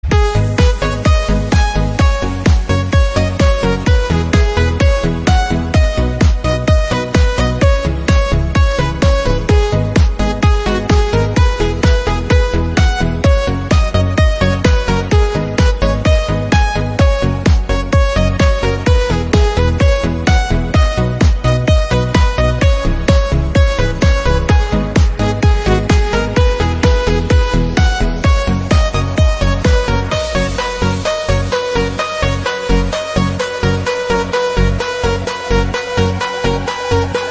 Nhạc EDM hót